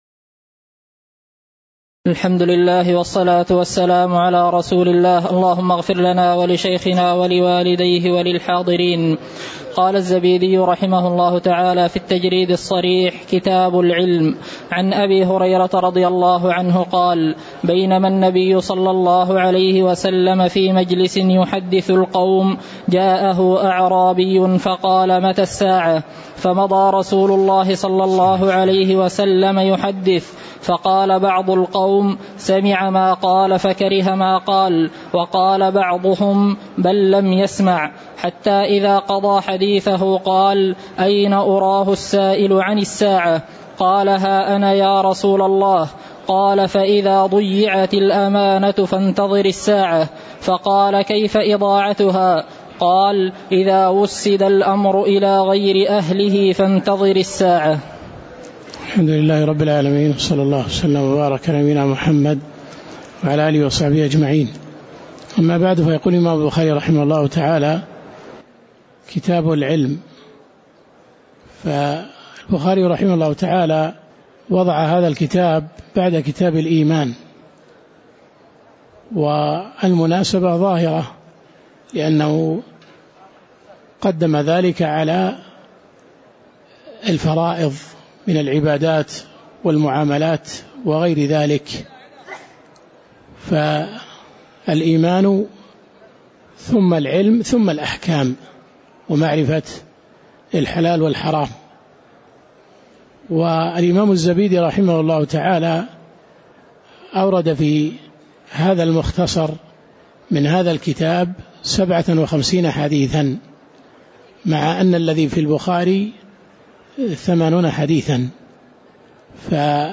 تاريخ النشر ٦ صفر ١٤٤٠ هـ المكان: المسجد النبوي الشيخ